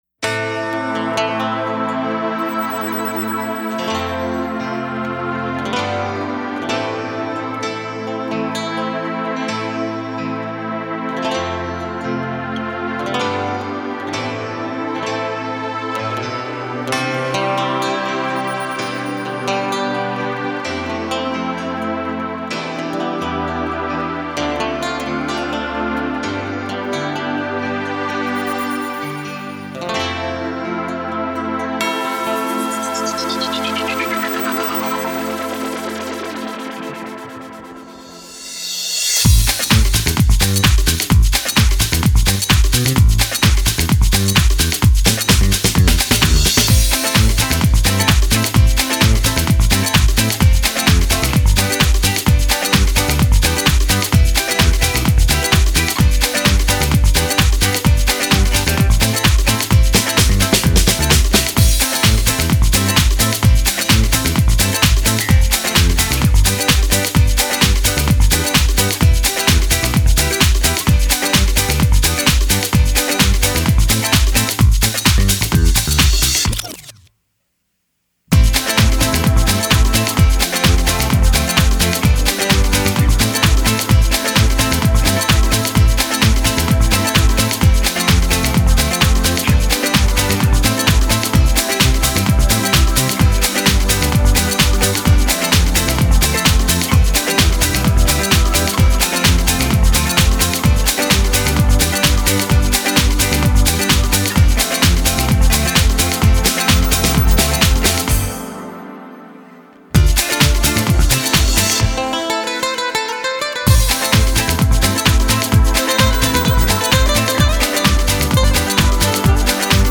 به سبک پاپ است.